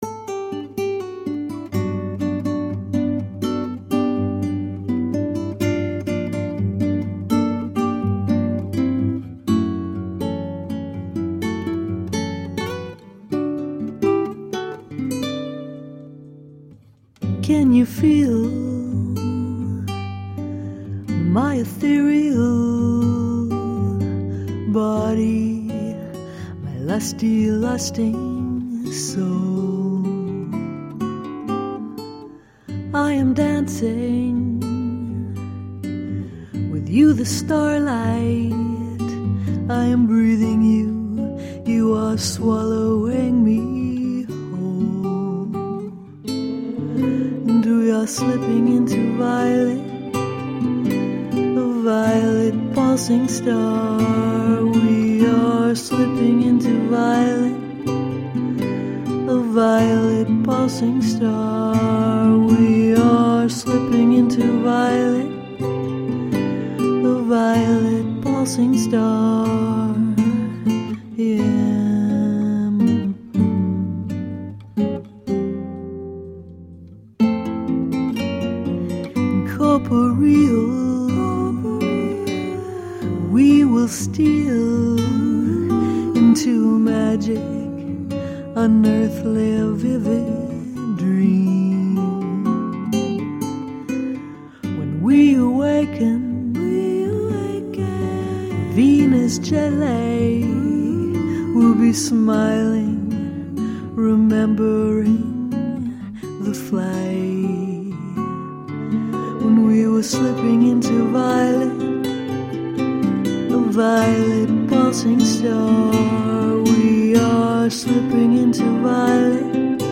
Jazzed up, funk-tinged eclectic pop..
Tagged as: Alt Rock, Rock, Ironic Rock